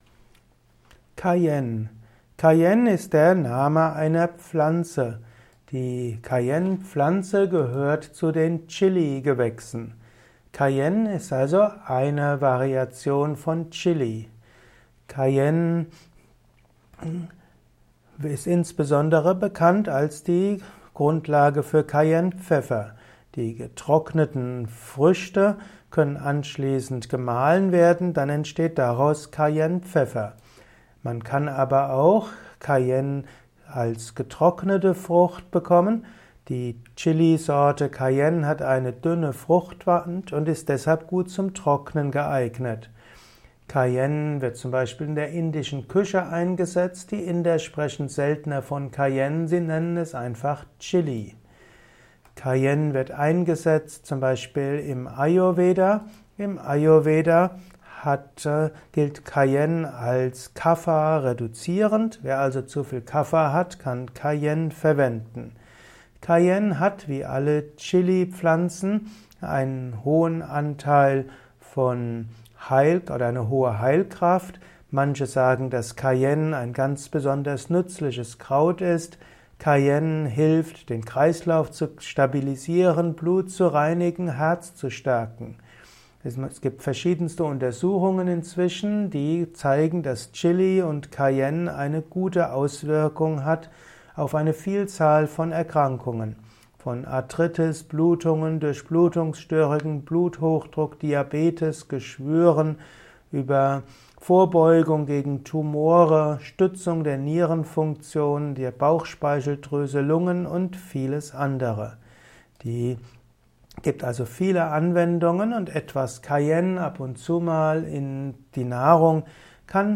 Erfahre Wissenswertes über Cayenne in diesem Kurzvortrag